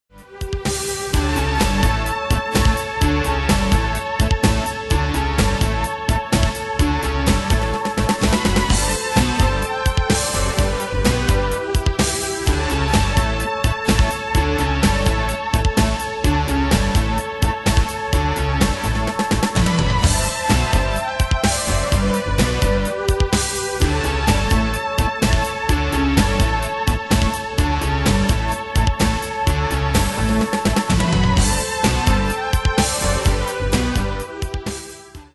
Style: PopAnglo Ane/Year: 1996 Tempo: 127 Durée/Time: 4.09
Danse/Dance: Techno Cat Id.
Pro Backing Tracks